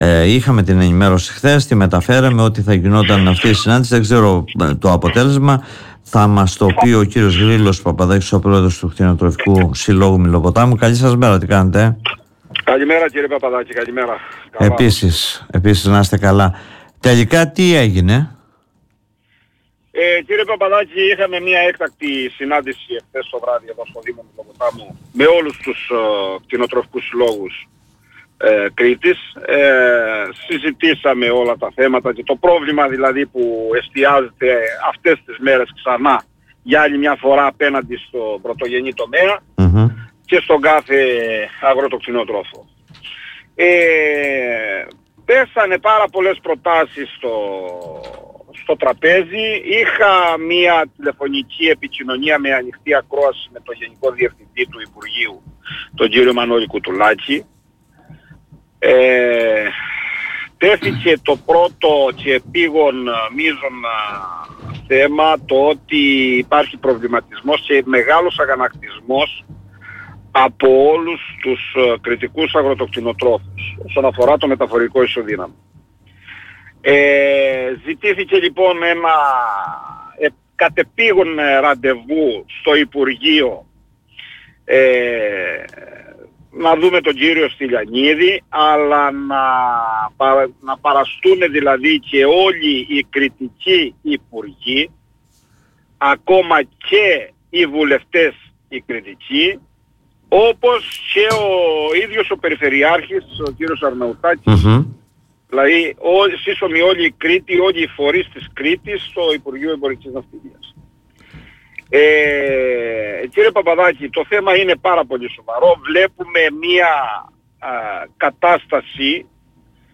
Μιλώντας στην εκπομπή “Δημοσίως” του politica 89.8 είπε πως υπήρξε τηλεφωνική επικοινωνία με τον Γενικό Γραμματέα του υπουργείου κ. Κουτουλάκη και ζητήθηκε να κλειστεί άμεσα η συνάντηση με τον υπουργό και στην οποία ζητούν την συμμετοχή του Περιφερειάρχη Σταύρου Αρναουτάκη, των υπουργών από την Κρήτη και των βουλευτών.